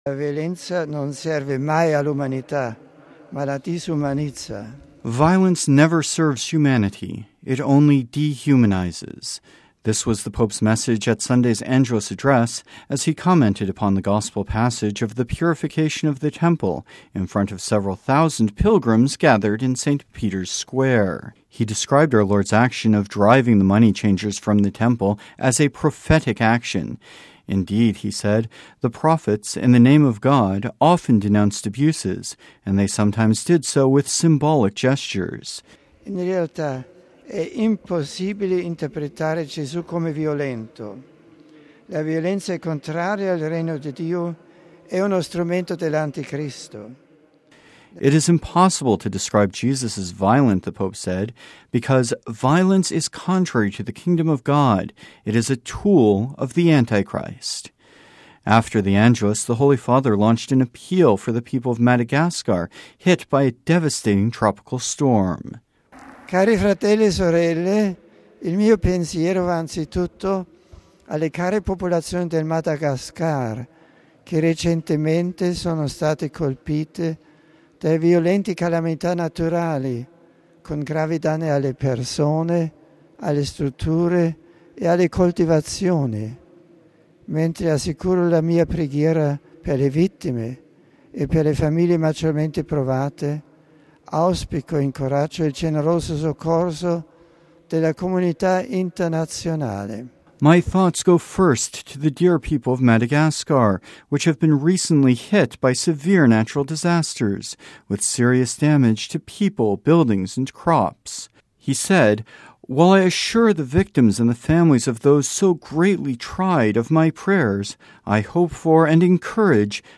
“Violence never serves humanity – it only dehumanises.” This was the Pope’s message at Sunday’s Angelus address, as he commented upon the Gospel passage of the Purification of the Temple.